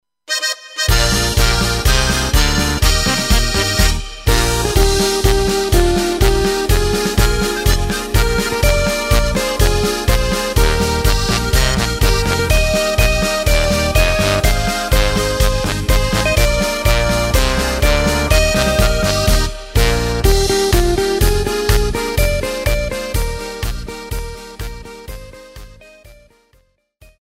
Takt:          2/4
Tempo:         124.00
Tonart:            Eb
Polka Trad.